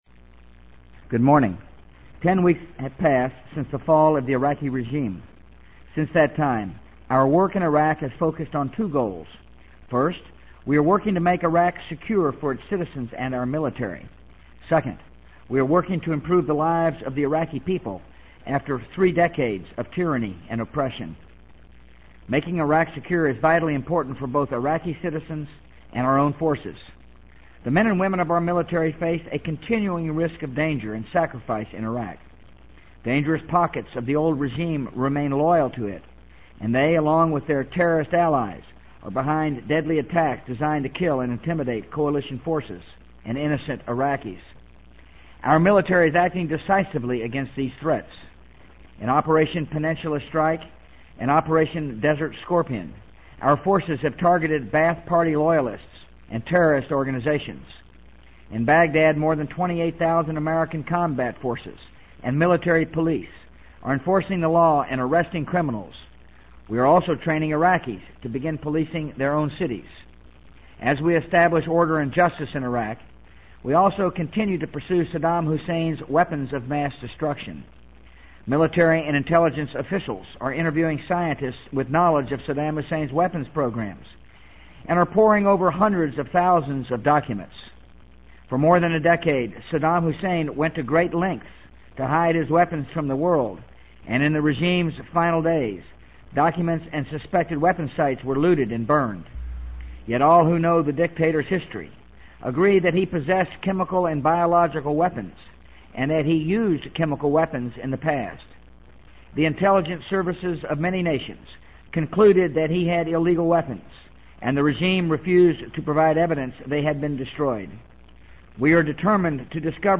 【美国总统George W. Bush电台演讲】2003-06-21 听力文件下载—在线英语听力室